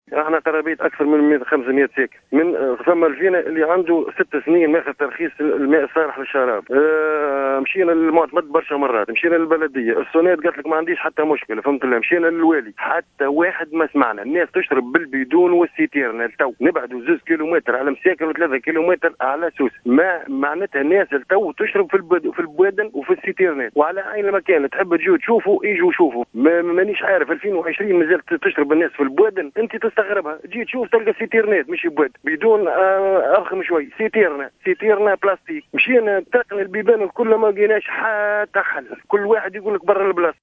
وقال أحد المواطنين في تصريح للجوهرة أف أم، إنّ حوالي 500 مواطن يقطنون المنطقة، يُجبرون على مشاركة حيواناتهم نفس صهاريج المياه "سيتيرنا"،أو الأوعية االبلاستكية لشرب الماء، رغم حصولهم على ترخيص من الشركة الوطنية لاستغلال وتوزيع المياه "الصوناد"، منذ أكثر من 6 سنوات، متوجها بنداء عاجل للسلطات الجهوية، لإيجاد حلّ في أقرب الآجال، خاصة في هذا الظرف الصحي الدقيق الذي يشهد انتشارا واسعا لفيروس كورونا.